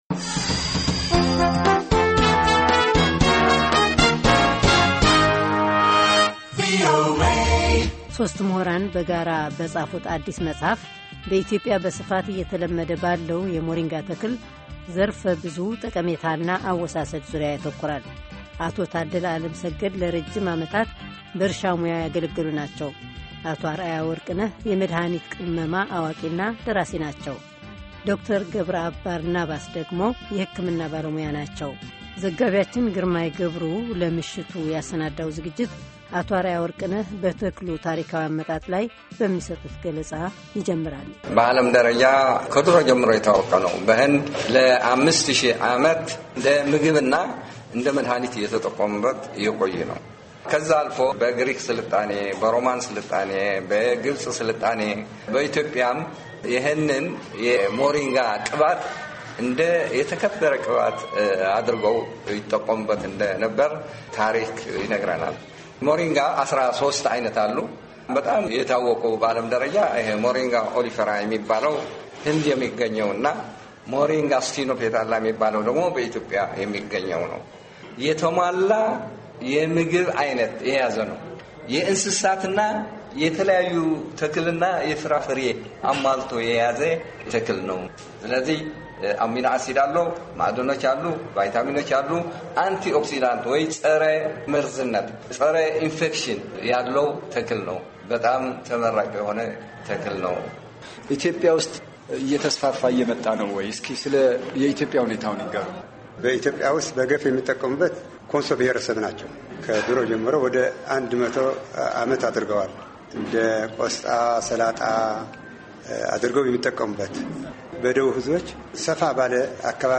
በሞሪንጋ ተክል ላይ የተካሄደ ውይይት